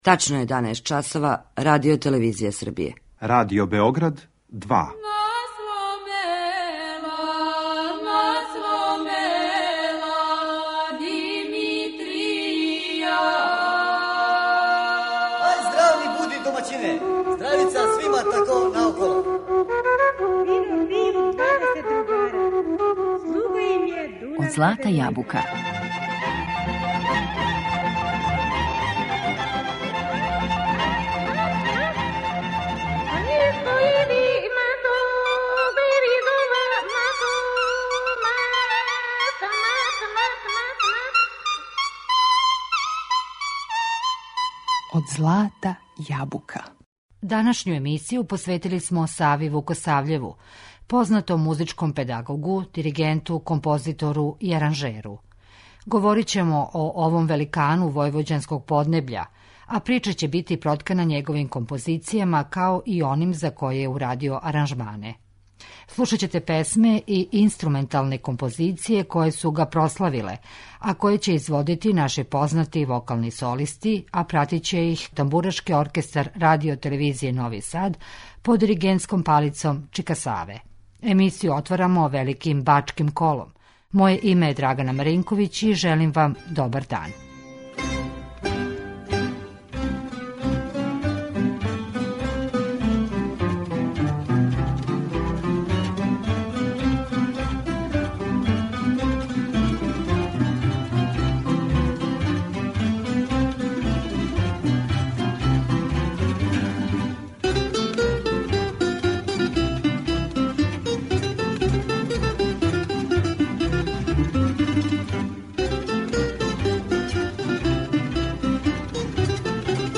Говорићемо о овом великану војвођанског поднебља, а прича ће бити проткана његовим композицијама. Слушаћемо песме и инструменталне композиције које су га прославиле у извођењу наших познатих солиста уз пратњу Тамбурашког оркестра Радио Новог Сада, под диригентском палицом чика Саве.